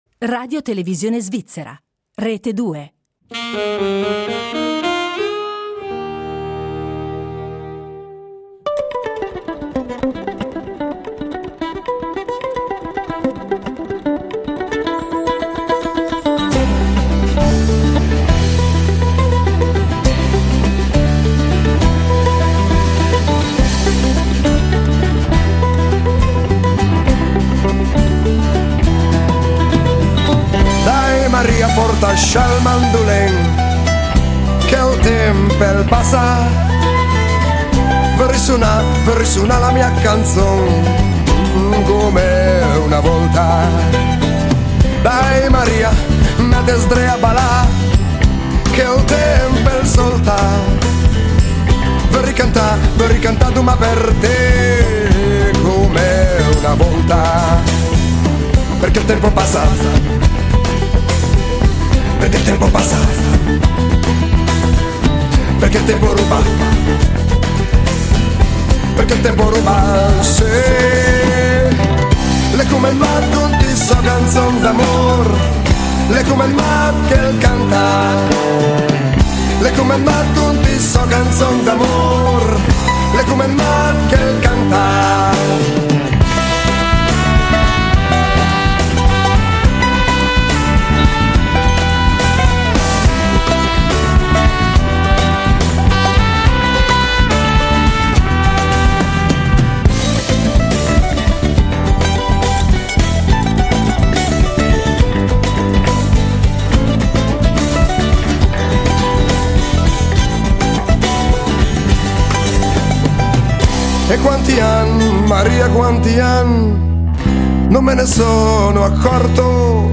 Il Cantautore dialettale ospite oggi alle 17:00